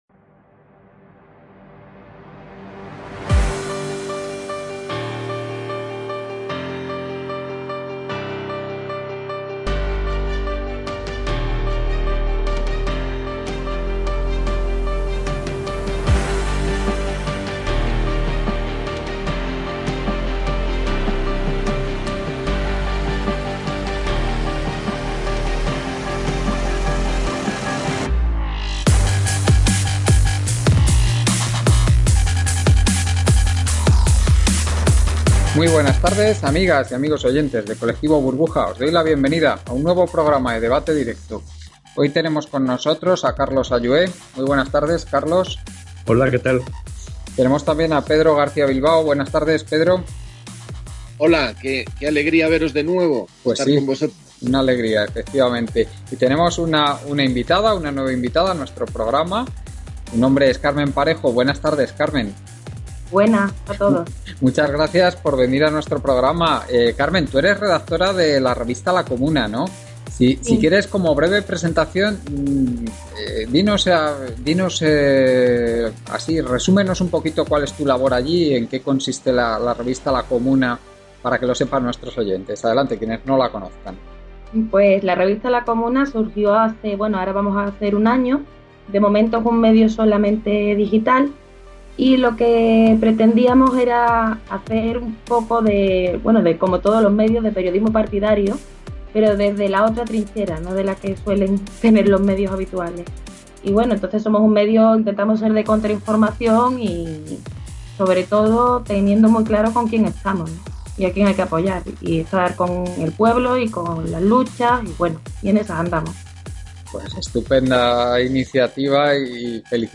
RADIO podcast.